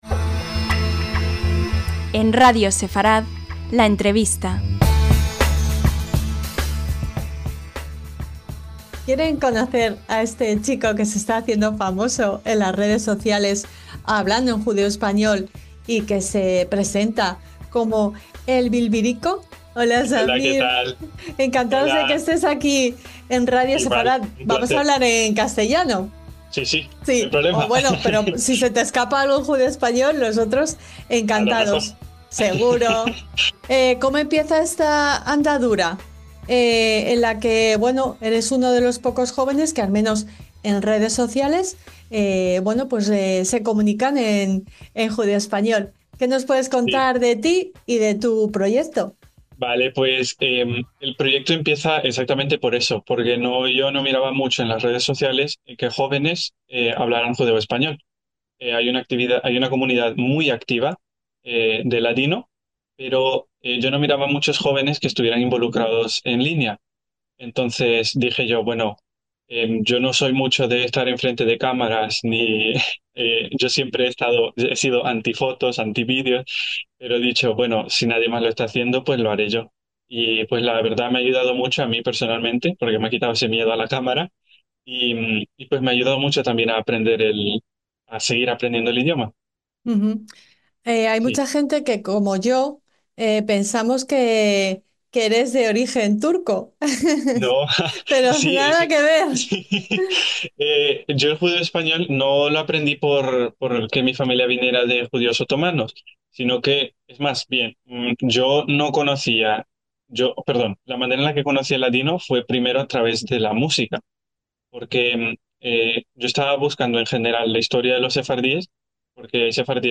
LA ENTREVISTA - Ola!